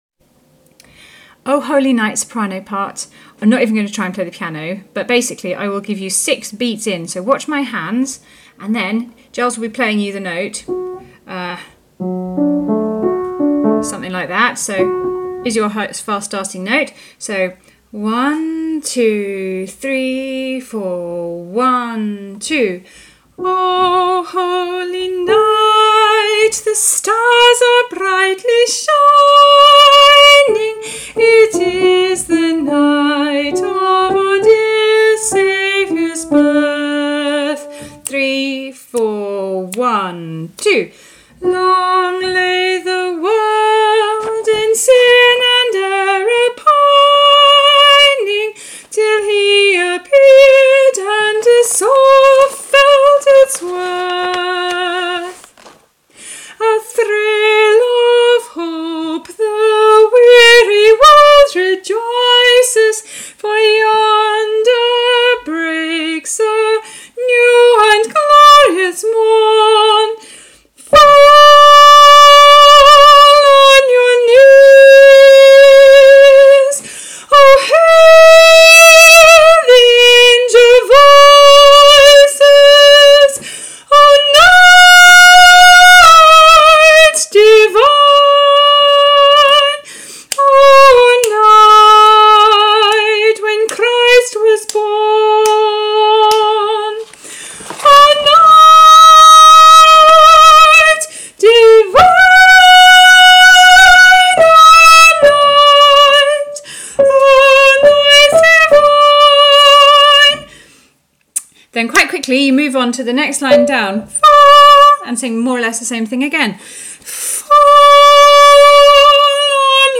Sopranos
O-Holy-Night-Soprano.mp3